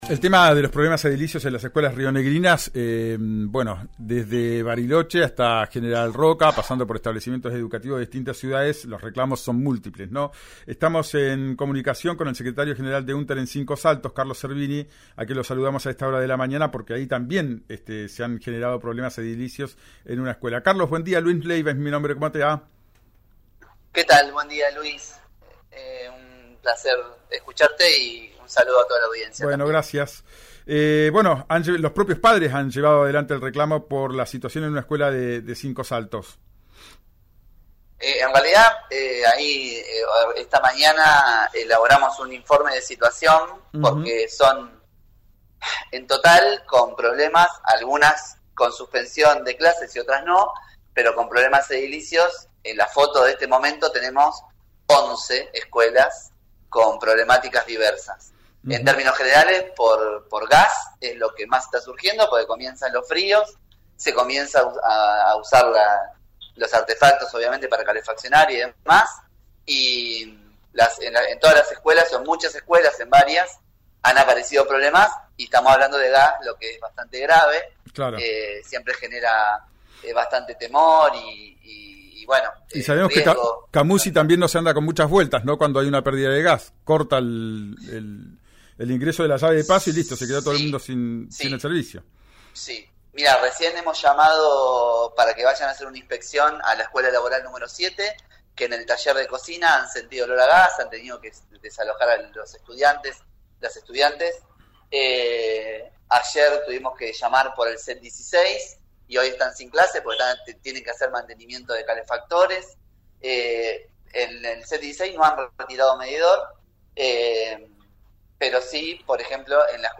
en RÍO NEGRO RADIO: